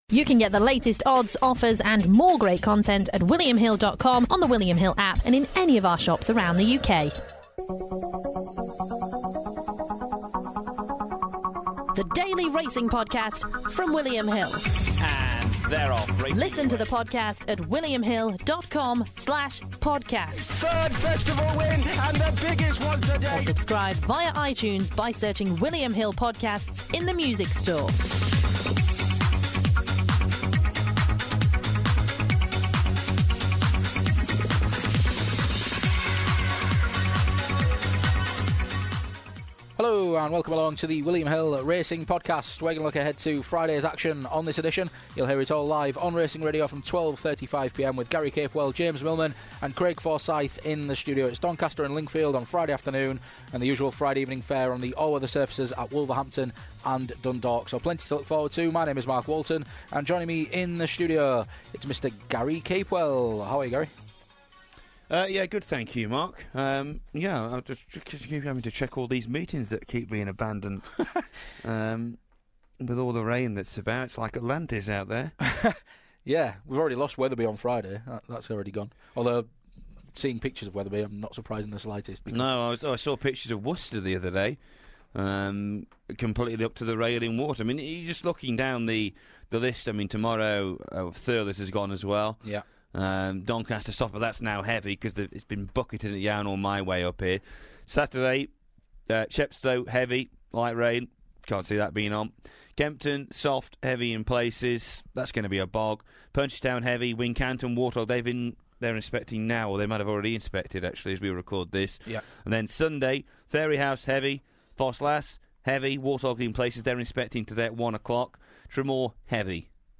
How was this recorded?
in the studio on this edition of the podcast